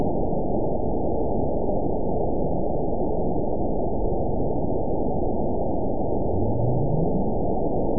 event 912056 date 03/17/22 time 04:14:45 GMT (3 years, 2 months ago) score 9.67 location TSS-AB01 detected by nrw target species NRW annotations +NRW Spectrogram: Frequency (kHz) vs. Time (s) audio not available .wav